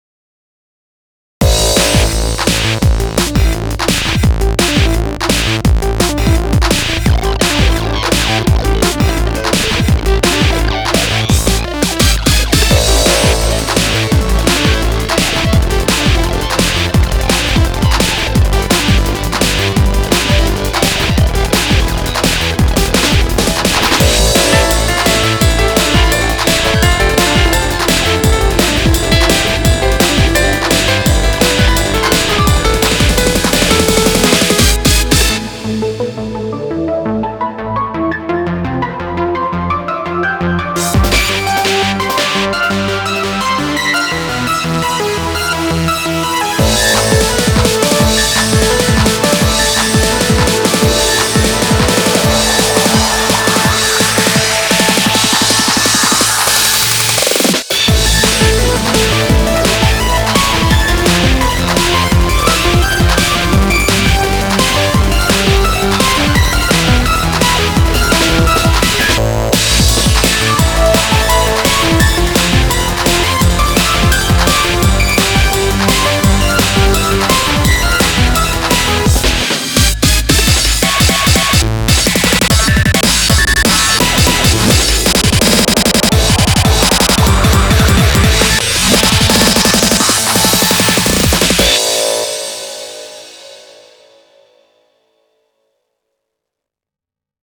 BPM85-170
MP3 QualityMusic Cut